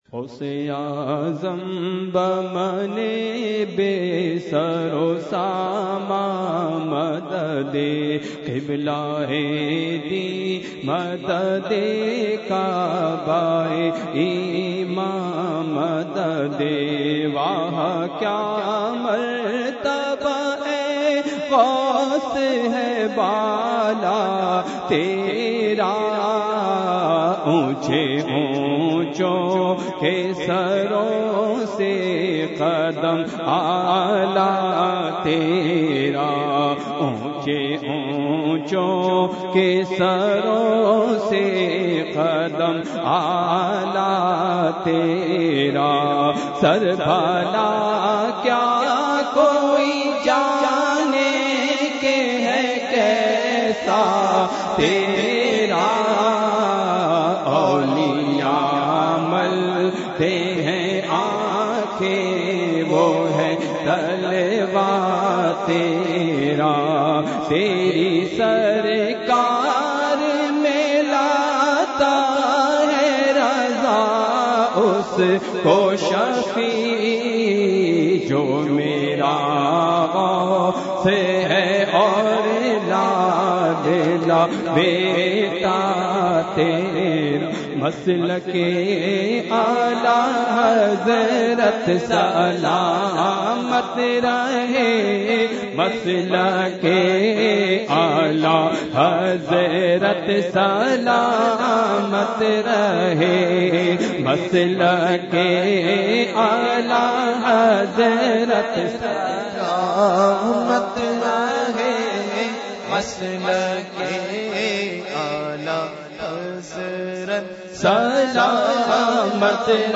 منقبت اور صلاۃ وسلام بسلسلہ عرسِ اعلیٰ حضرت علیہ رحمہ ۱۴۳۸ھ